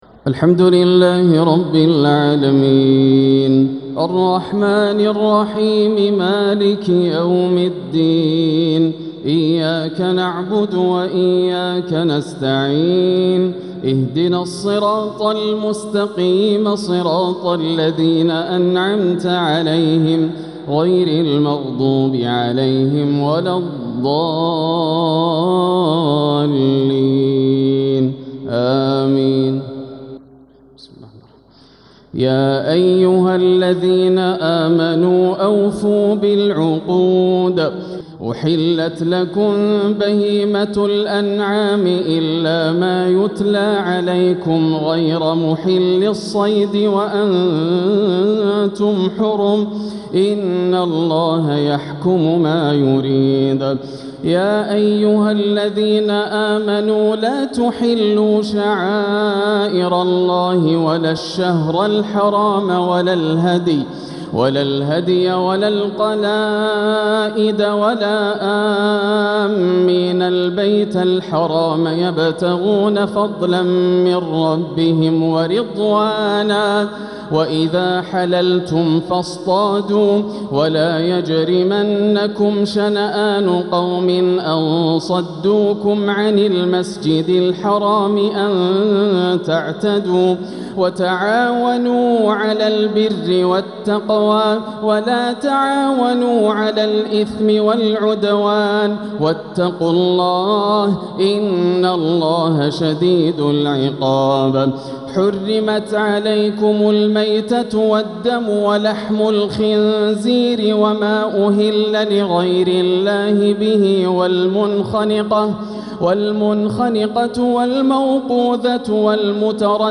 بأجمل ترتيل وأحسنه يرتل الشيخ ياسر الدوسري آيات من سورة المائدة | تراويح الليلة الثامنة ١٤٤٦ هـ > الليالي الكاملة > رمضان 1446 هـ > التراويح - تلاوات ياسر الدوسري